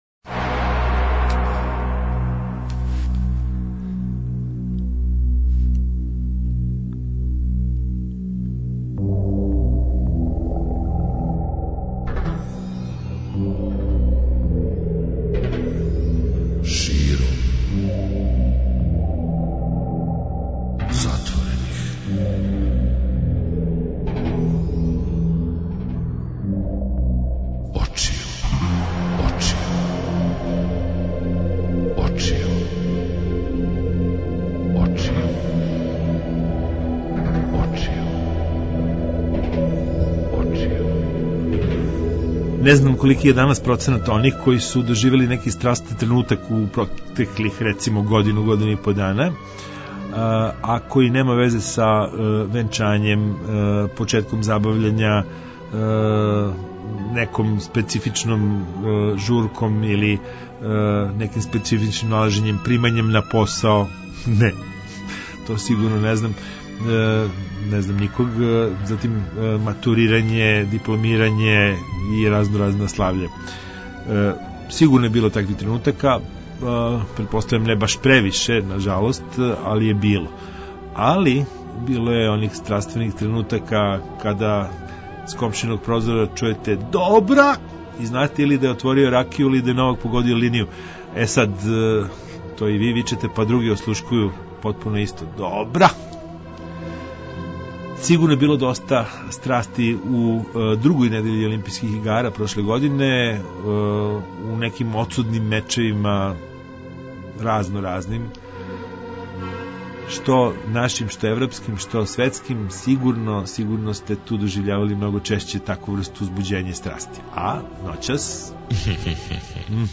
Приче о спорту, навијачима, необичним спортским догађајима, предвиђања, коментари, прогнозе и сл. Гости - спортски новинари